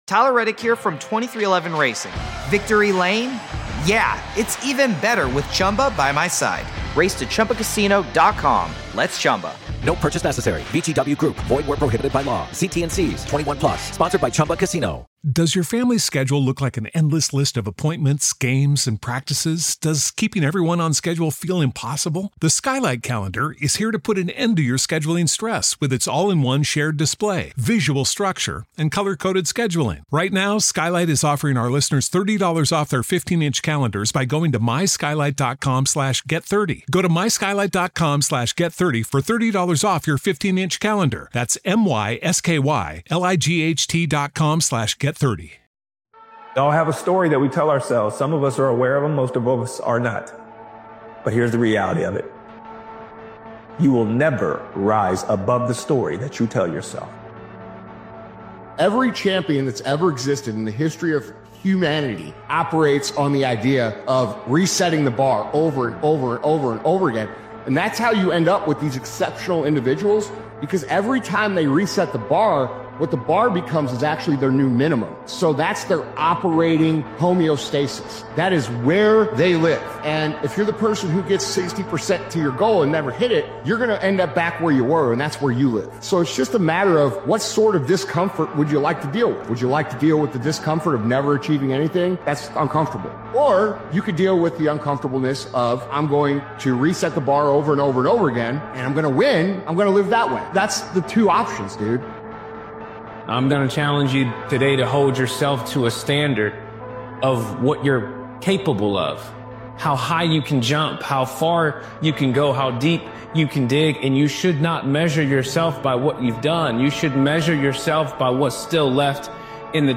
This powerful motivational speeches compilation is about turning intention into action—breaking old patterns and committing to real progress.